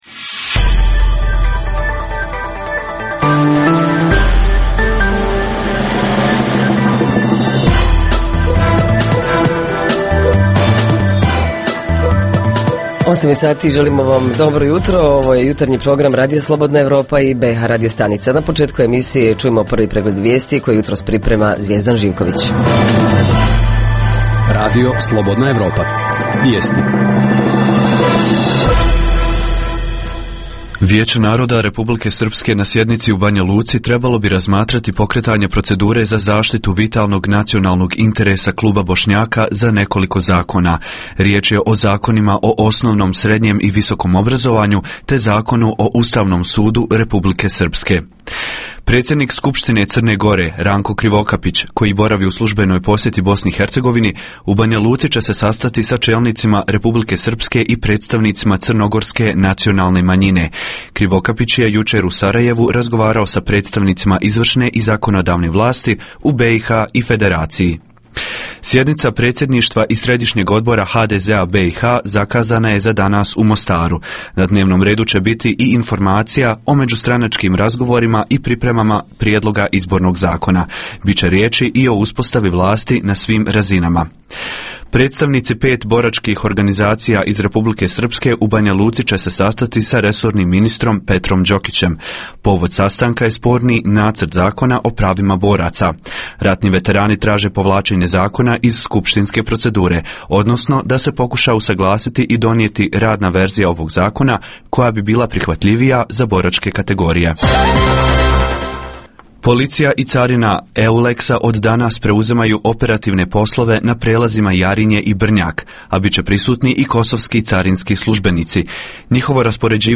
Lokalne zajednice odnosno opština u BiH - kolika je moć opštine, koliko se poštuju, razmatraju i uzimaju u obzir inicijative koje prema kantonima ili entitetima idu iz opštine? Reporteri iz cijele BiH javljaju o najaktuelnijim događajima u njihovim sredinama.
Redovni sadržaji jutarnjeg programa za BiH su i vijesti i muzika.